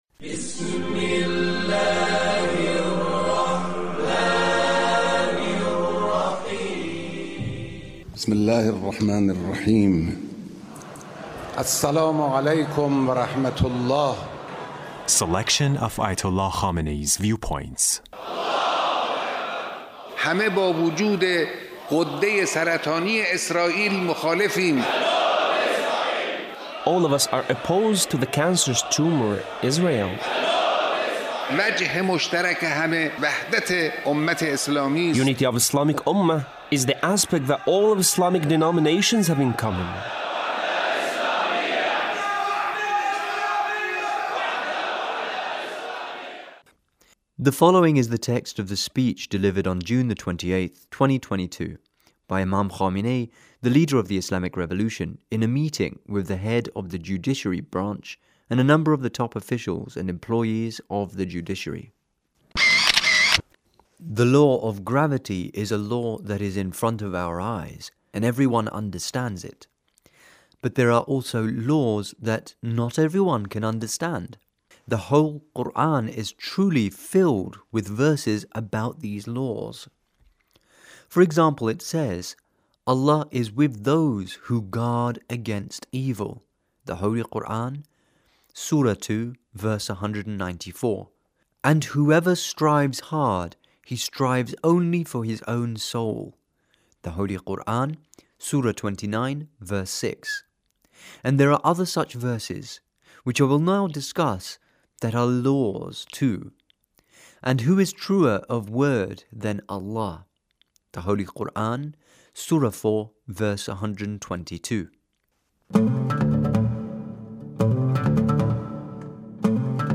Leader's speech (1458)
The Leader's speech in a meeting with a number of the top officials and employees of the Judiciary.